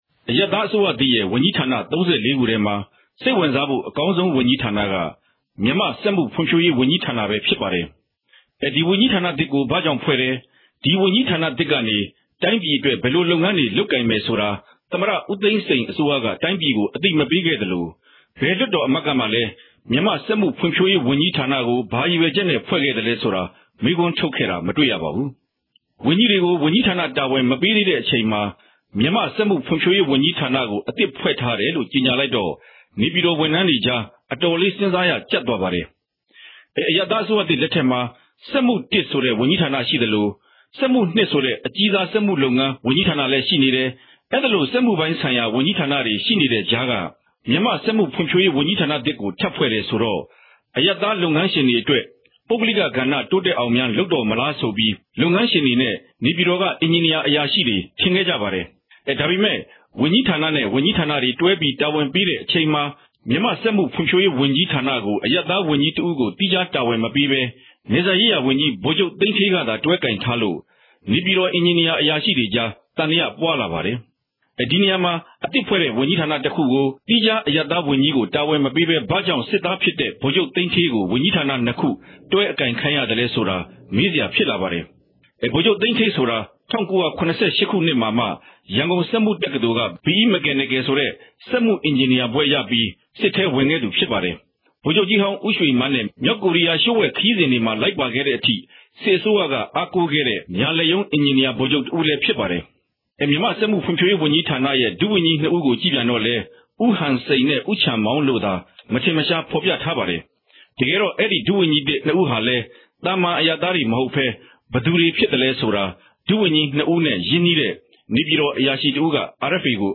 သုံးသပ်တင်ပြချက်။